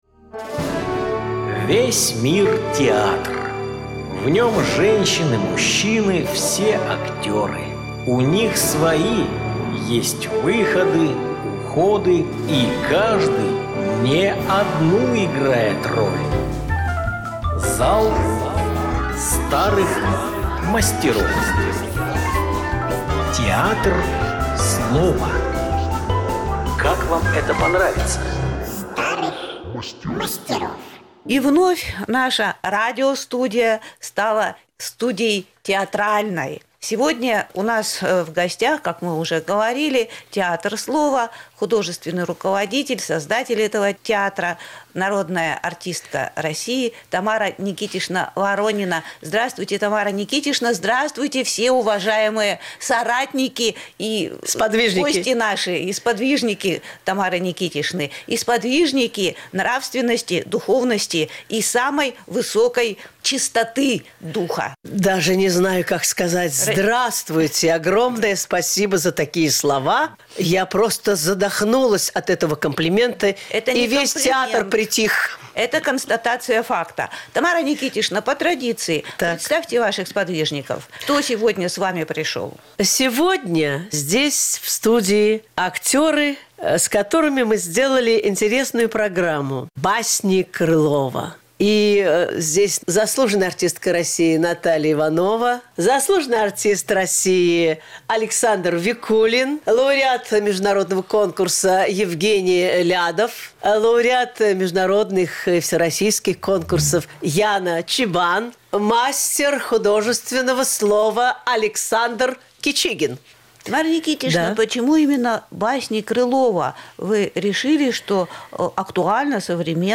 В нашей программе сегодня:Радиоспектакль”Международное рукопожатие Украине”